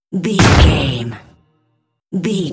Cinematic stab hit trailer
Sound Effects
Atonal
heavy
intense
dark
aggressive